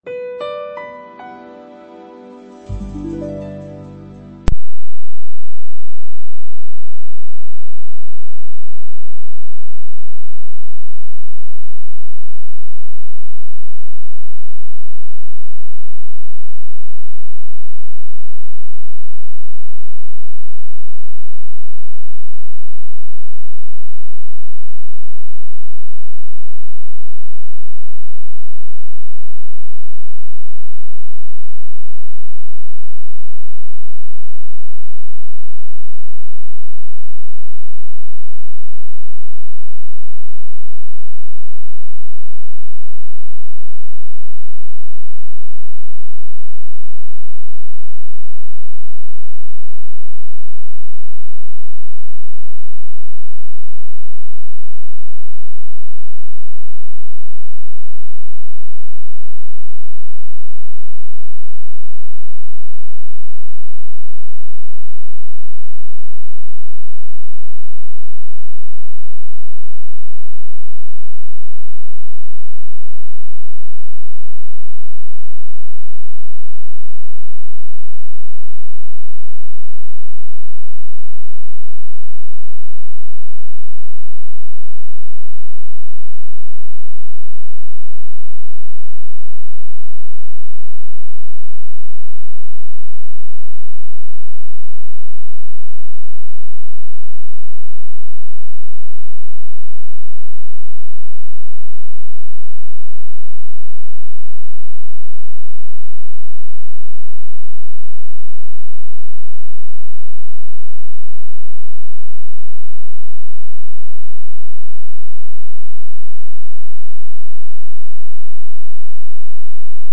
Tutoría grupal sobre Trastornos Psicosomáticos